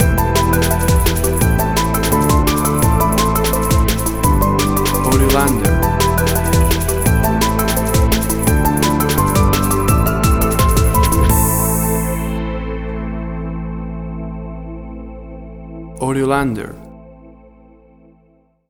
Tempo (BPM): 170